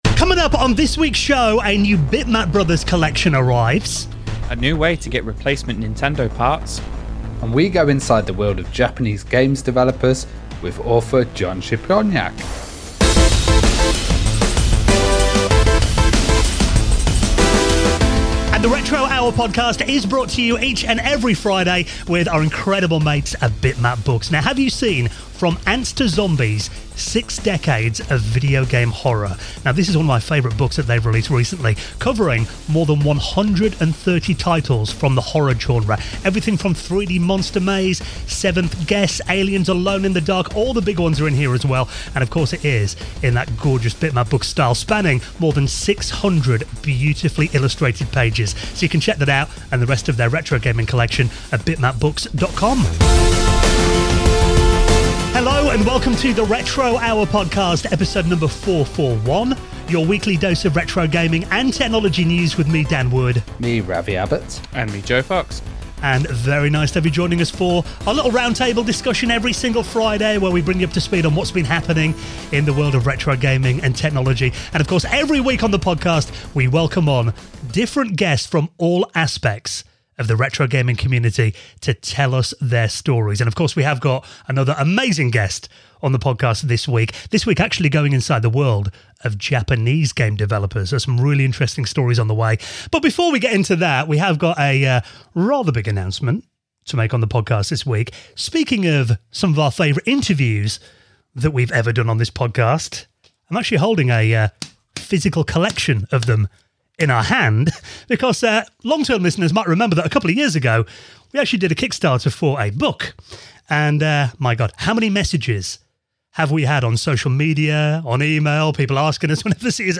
The Week's Retro News Stories